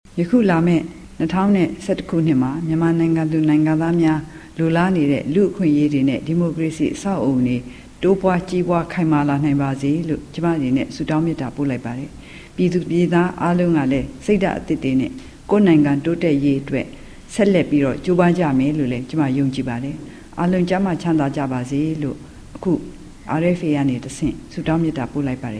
မြန်မာ့ ဒီမိုကရေစီခေါင်းဆောင် ဒေါ်အောင်ဆန်းစုကြည်က မြန်မာ ပြည်သူလူထု တရပ်လုံးနဲ့ တကမ္ဘာလုံးက မြန်မာ့ ဒီမိုကရေစီရေး ထောက်ခံသူ အားလုံးကို နှစ်သစ်ကူး နှုတ်ခွန်းဆက်စကား ပြောလိုက်ပါတယ်။